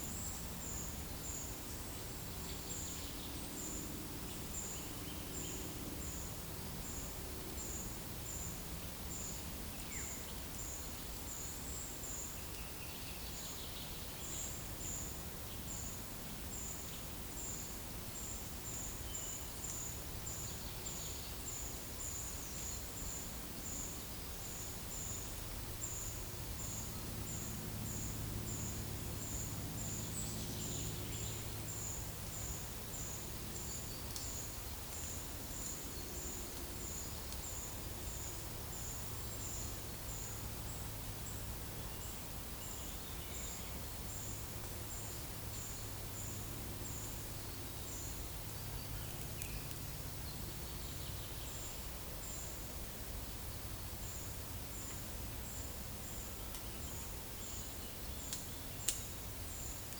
Monitor PAM
Certhia familiaris
Turdus iliacus
Certhia brachydactyla